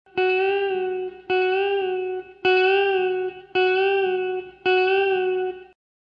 The third bend is a bend-and-release of 1/2 step on the second string 7th fret.